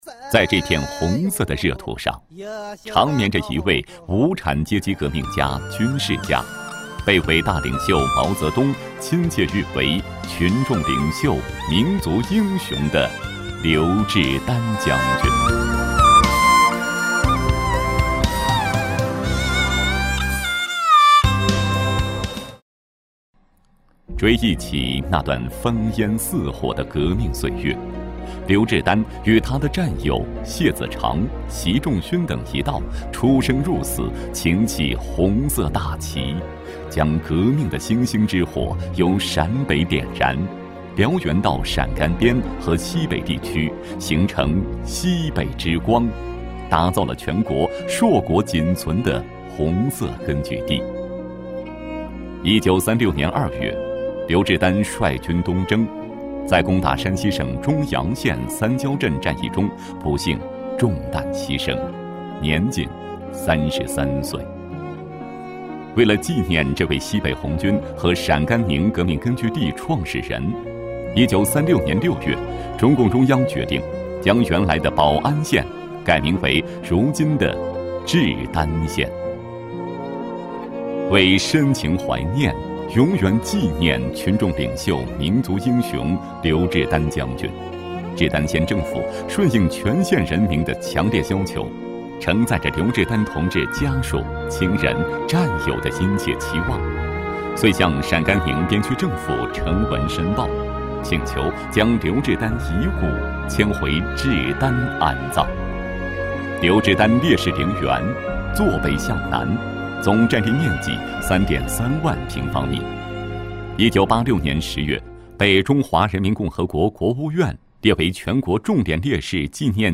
【红色档案诵读展播】“志丹”精神代代相传